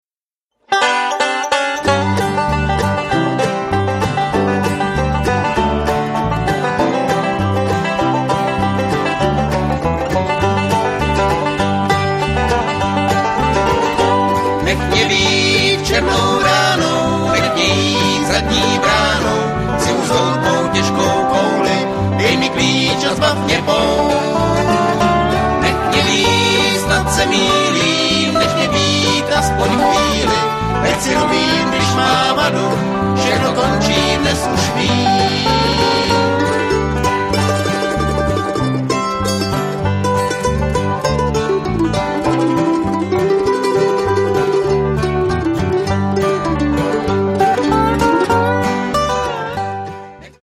guitar, vocal
banjo, vocal